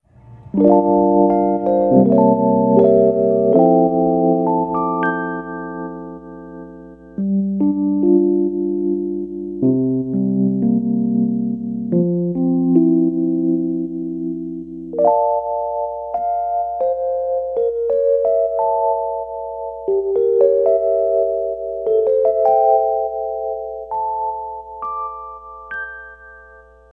nice-alarm.wav